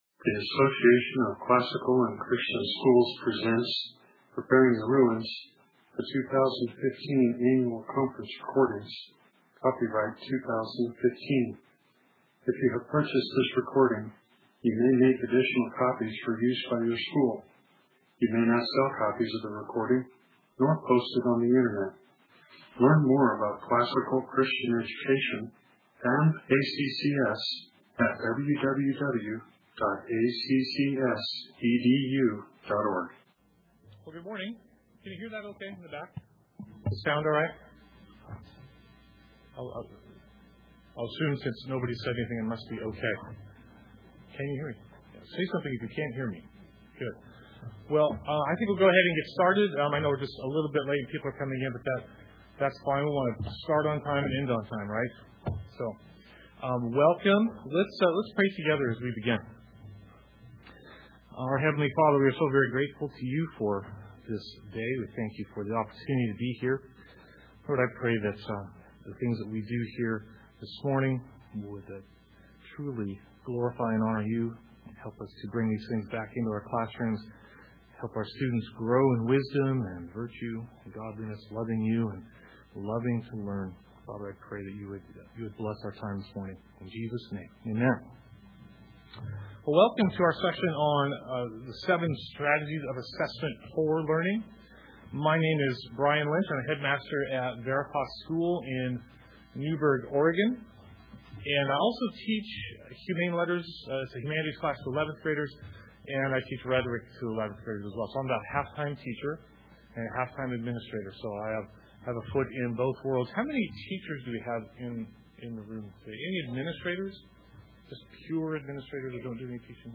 2015 Workshop Talk | 2015 | All Grade Levels, General Classroom